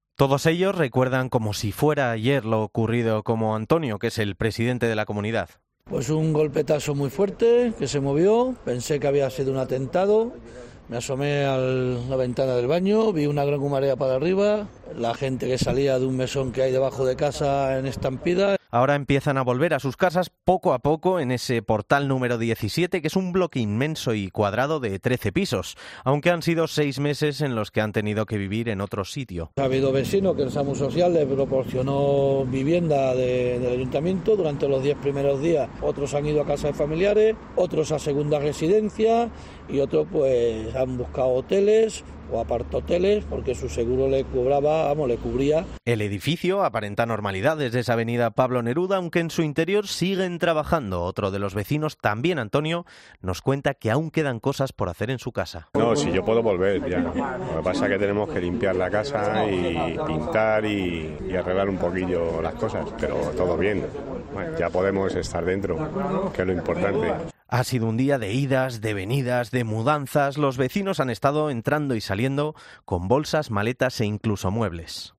En COPE Madrid hemos podido hablar con alguno de ellos para contarnos cómo están, cómo han vivido estos últimos seis meses, qué recuerdan y cómo está siendo la vuelta a la rutina.